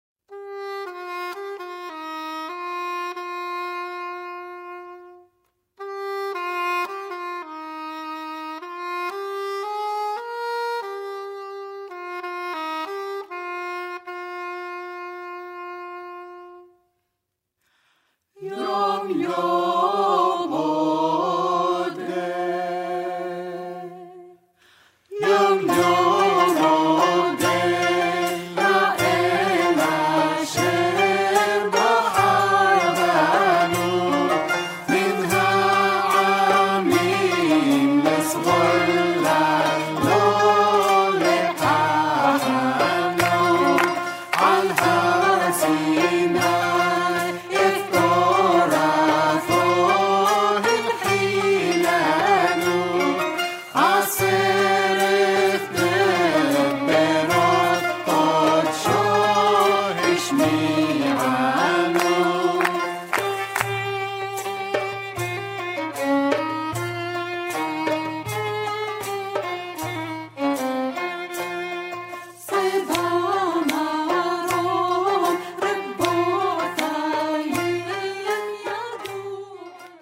Iraqi Jewish and Arabic Song.